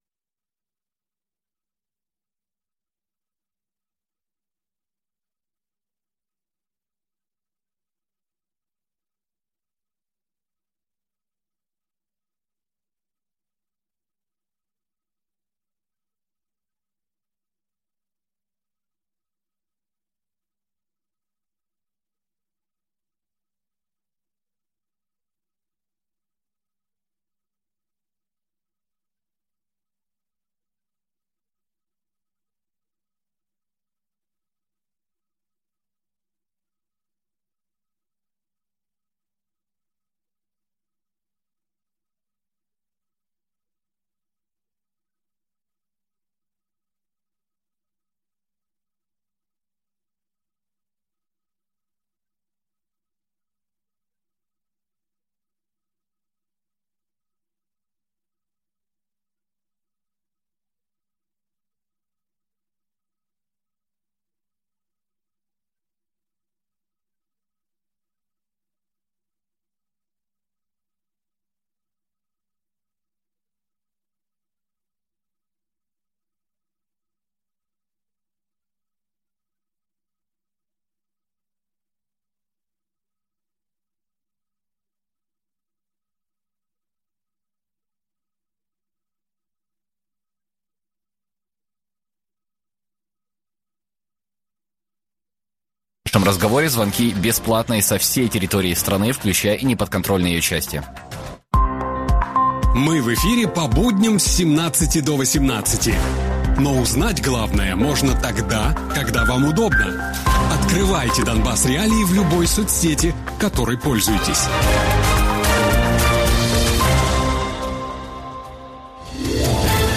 Гості програми радіо Донбас.Реалії
політологиня